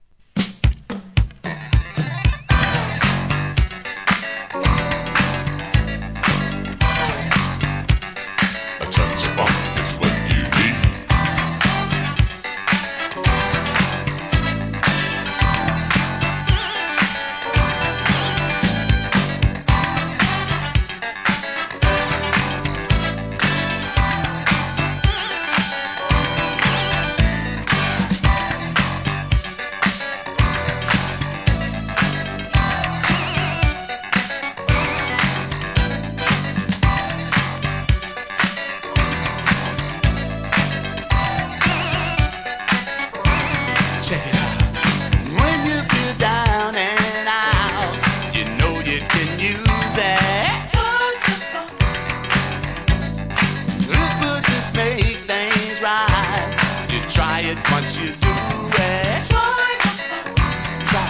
SOUL/FUNK/etc... 店舗 ただいま品切れ中です お気に入りに追加 文句なしでかっこいいシカゴ産FUNK!!